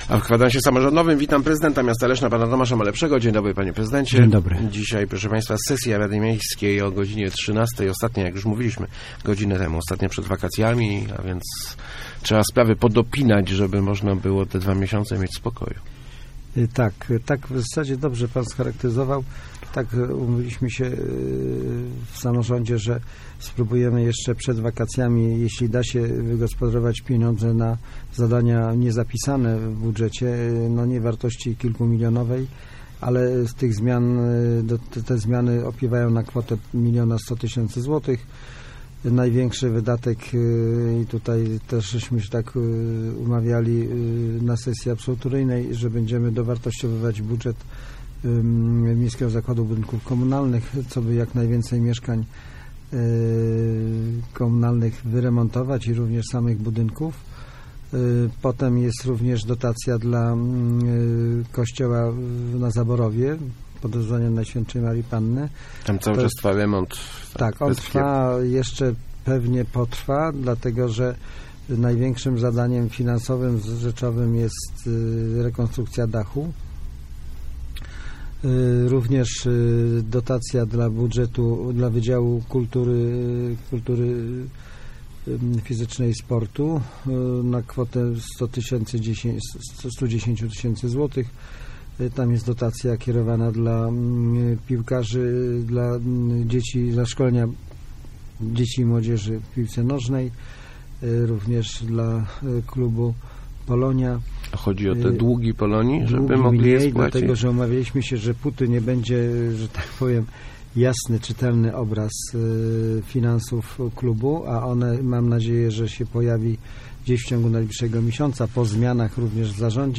Gościem Kwadransa był prezydent Tomasz Malepszy. ...